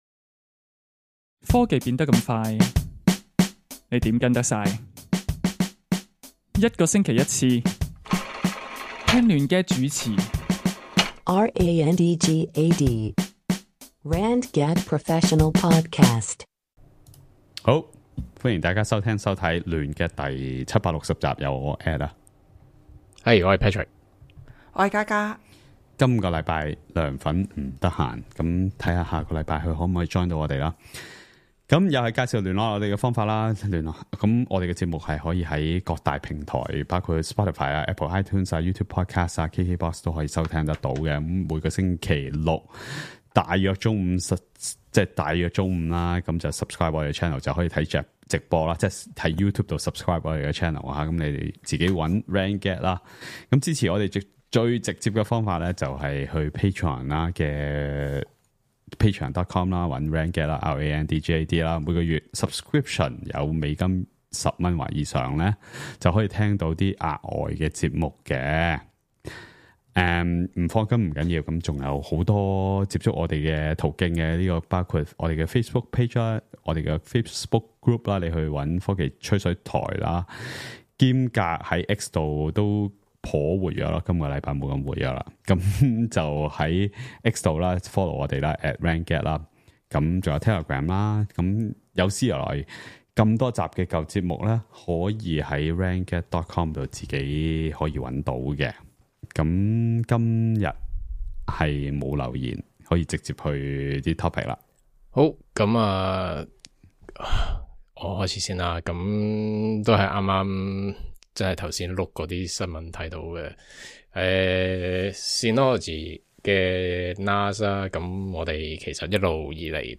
搜羅最新科技資訊、數碼產品，由四位主持，從不同立場出發，以專業角度分析，每星期一集既網上電台節目 - 亂gad！